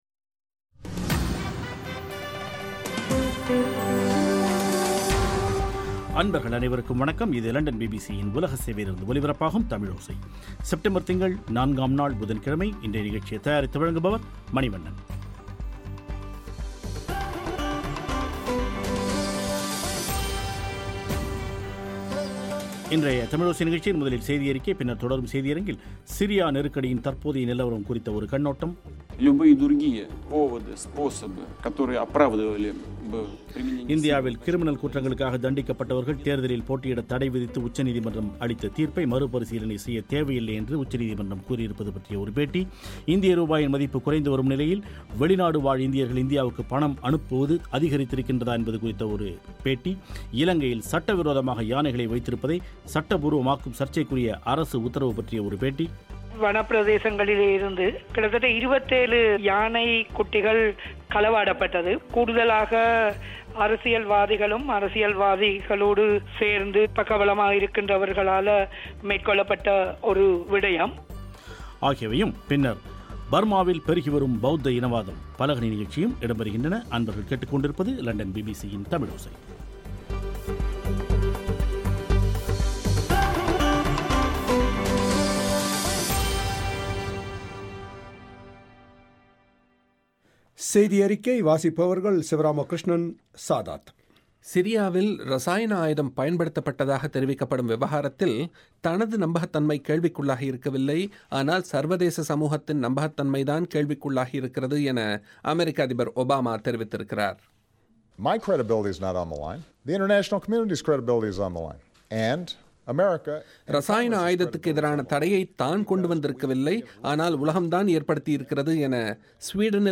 இந்தியாவில் கிரிமினல் குற்றங்களுக்காக தண்டிக்கப்பட்டவர்கள் தேர்தலில் போட்டியிட்த் தடை விதித்து உச்சநீதிமன்றம் அளித்த தீர்ப்பை மறு பரீசலனை செய்யத்தேவை இல்லை என்று உச்சநீதிமன்றம் கூறியிருப்பது பற்றிய ஒரு பேட்டி